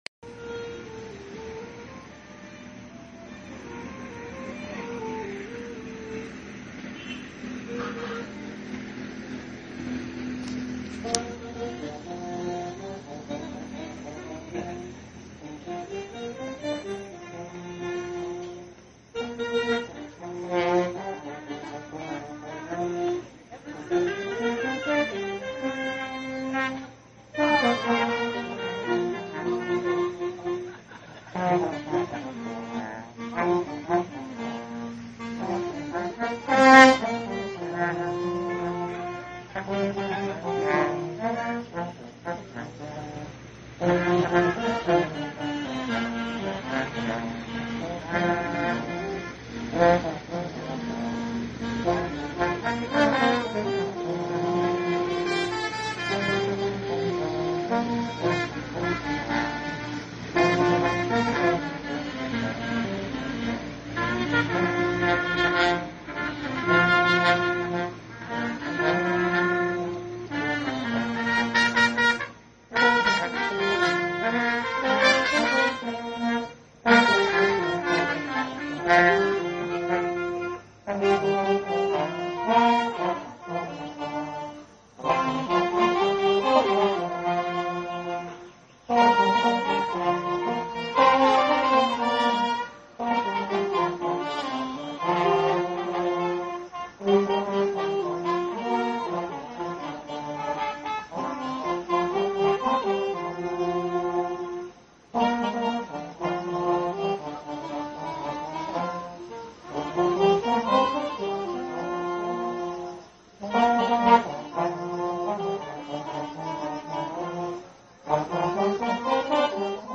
贝斯
节奏和那个响着警报声的合成器
钢琴和萨克斯
声乐样本
声道立体声